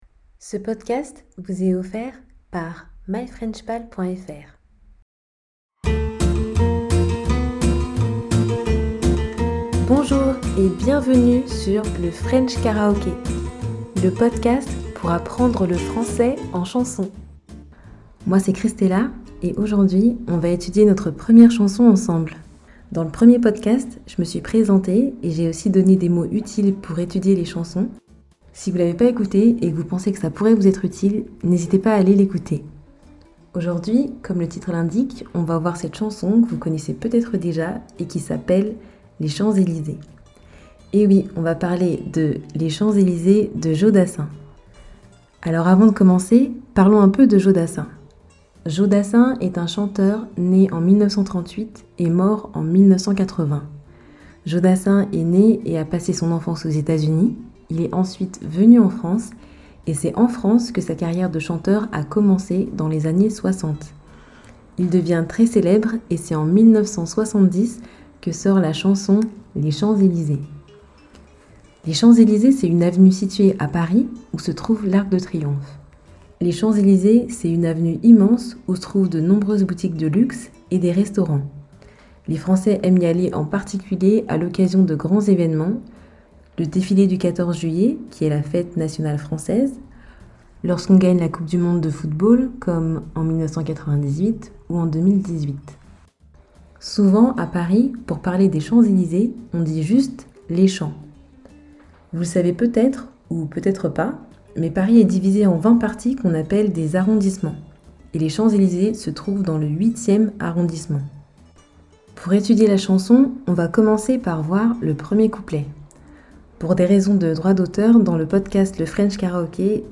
Dans cet épisode, on va étudier notre première chanson en vitesse parlée normale. Commençons notre aventure musicale avec cette chanson qui célèbre l'une des avenues françaises les plus célèbres au monde.